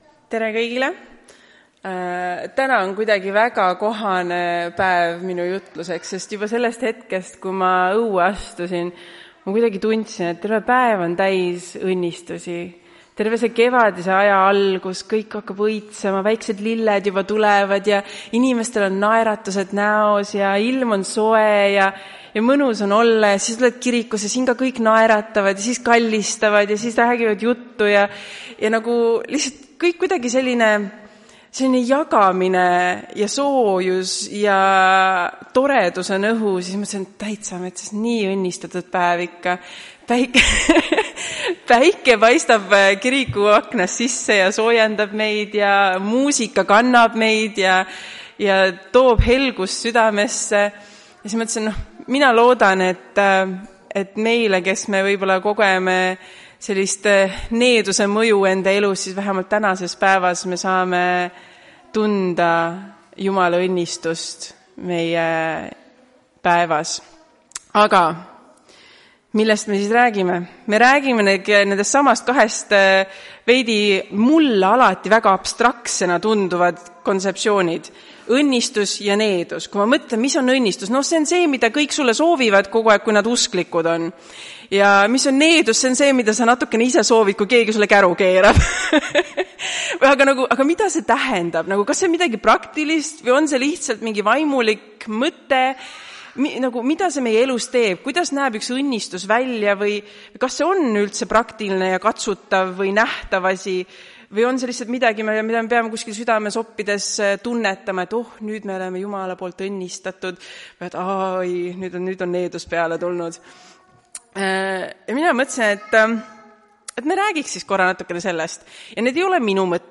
Tartu adventkoguduse 29.03.2025 hommikuse teenistuse jutluse helisalvestis.